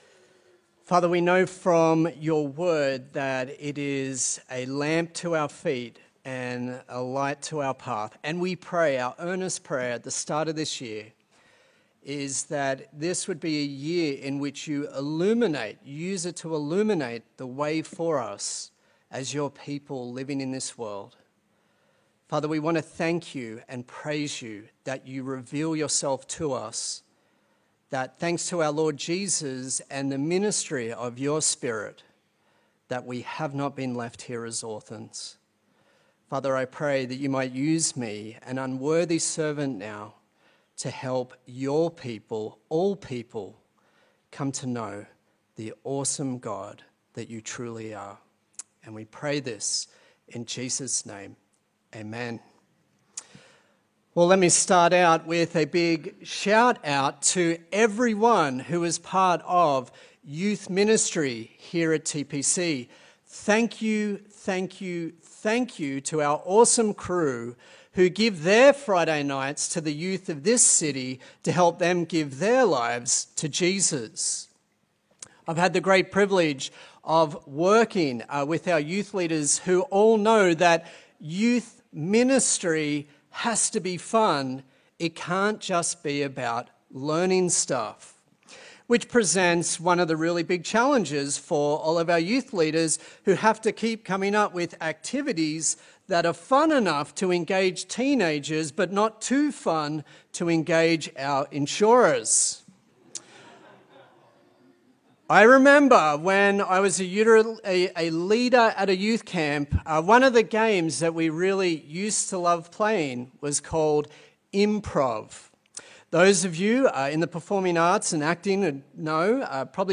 Acts Passage: Acts 17:16-34 Service Type: Sunday Service A sermon in the series on the book of Acts